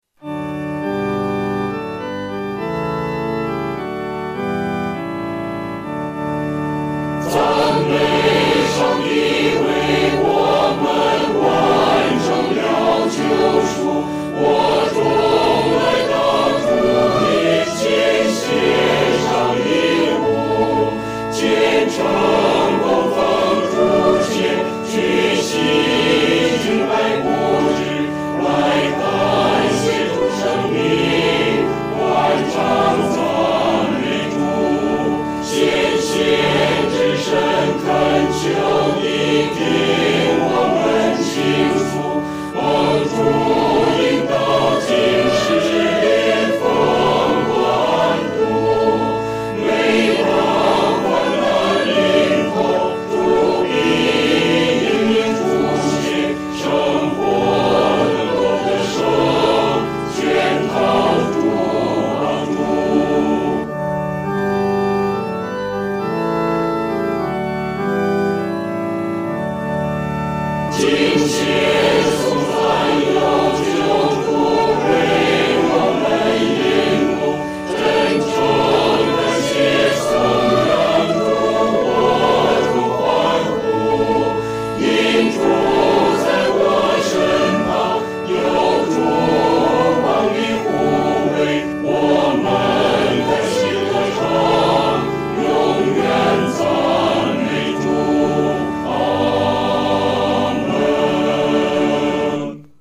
合唱
四声
本首圣诗由网上圣诗班（环球）录制
这首曲调格式简单，节奏鲜明，感情丰富，旋律多变，如同海浪起伏。